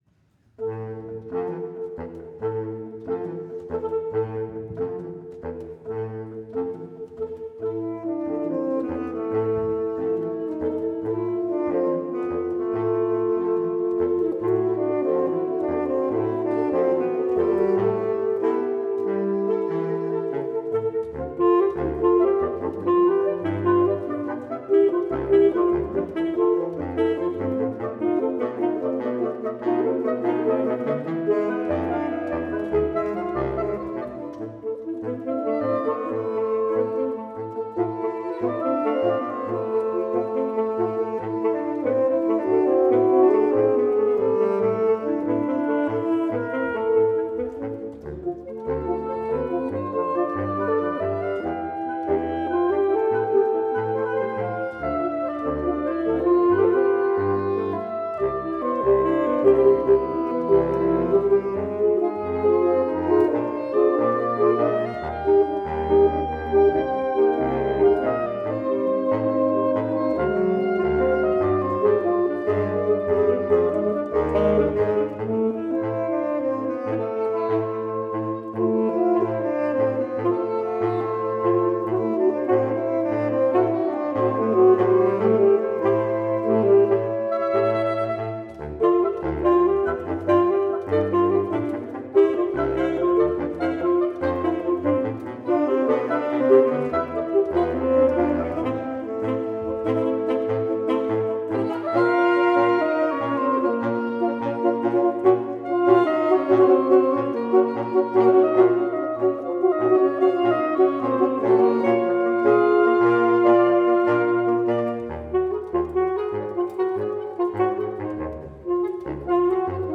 Saxofoonkwintet
sopraansaxofoon
altsaxofoon
tenorsaxofoon
baritonsaxofoon.